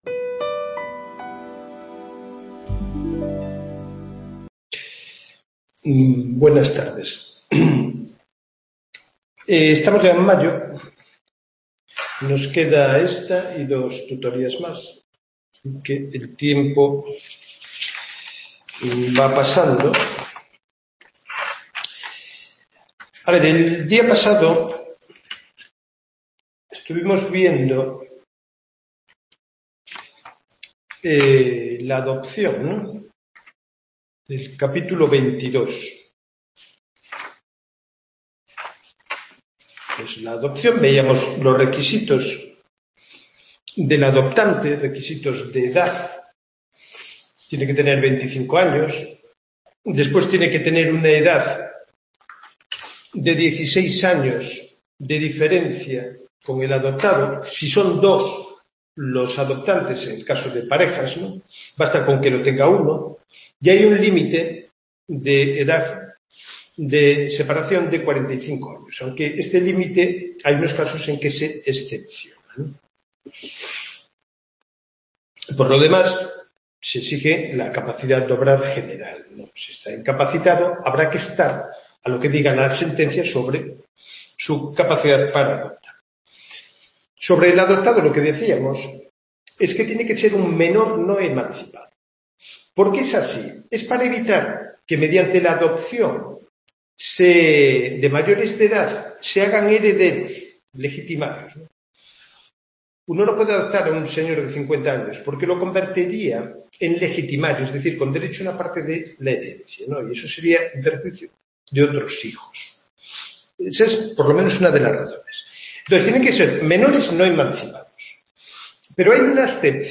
Tutoría.